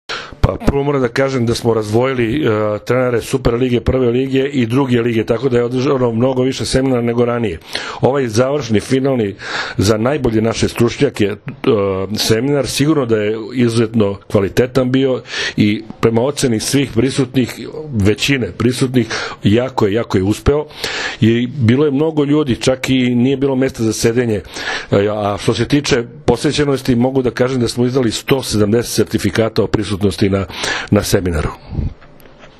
UDRUŽENJE ODBOJKAŠKIH TRENERA SRBIJE – SEMINAR „TRENERI ZA POBEDE 2016.“
IZJAVA